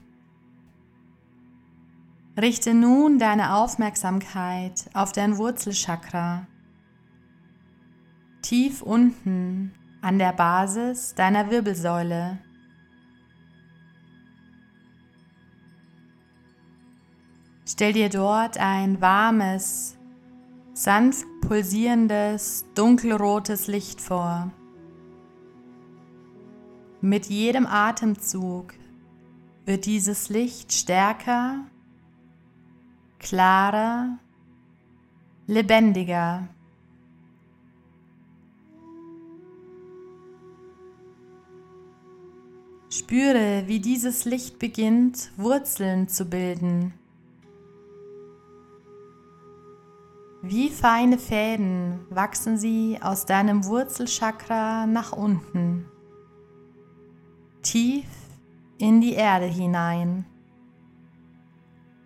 • Format: Geführte Meditationen (Audio-Datei)
• mit Musik unterlegt